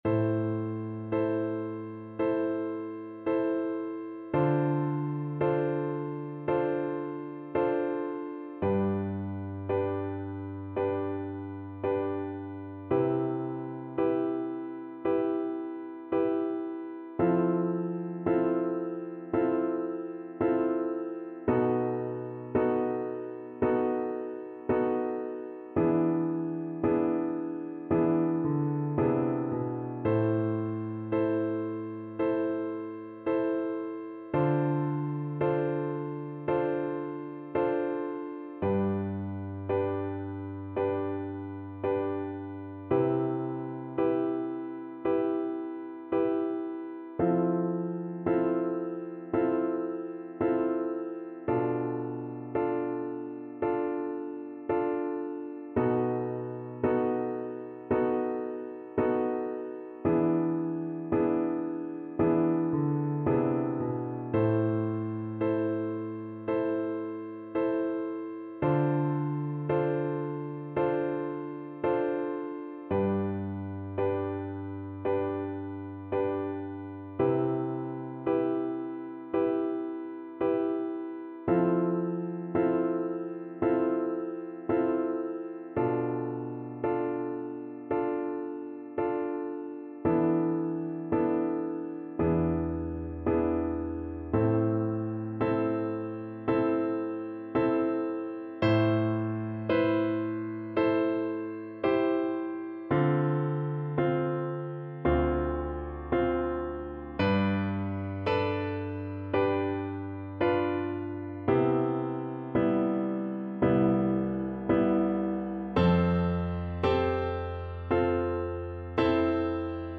Soprano (Descant) Recorder version
4/4 (View more 4/4 Music)
Andante
Classical (View more Classical Recorder Music)